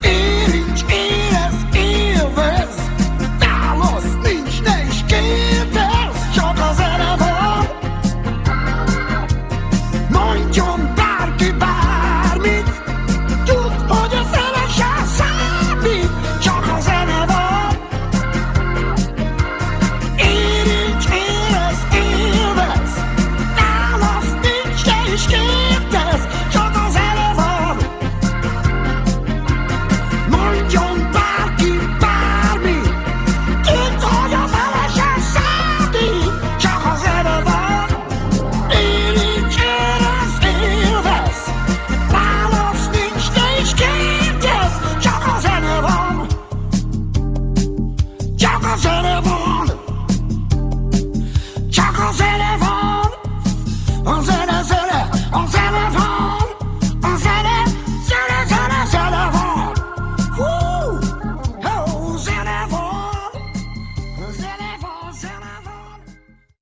Lattmann Béla: Bass